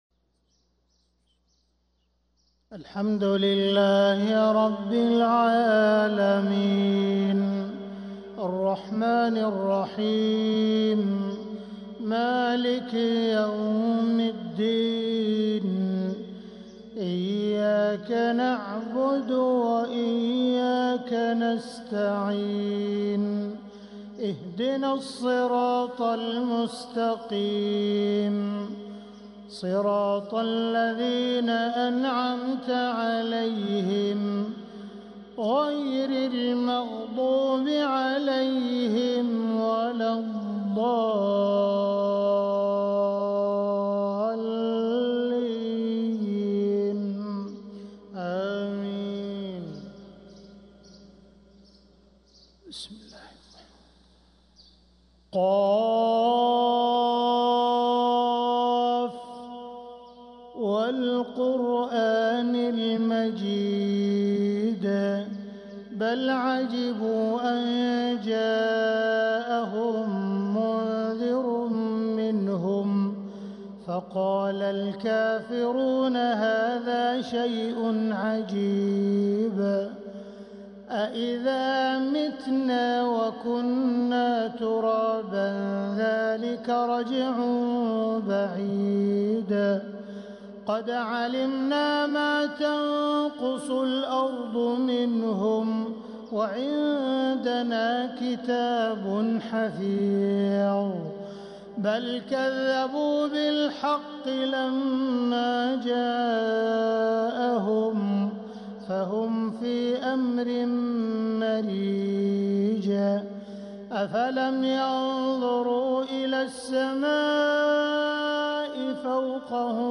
روائع الفجر | فجريات شهر رجب 1446هـ للشيخ أ.د. عبدالرحمن السديس من رحاب المسجد الحرام > إصدارات "وقرآن الفجر" > إصدارات منوعة 🕋 > المزيد - تلاوات الحرمين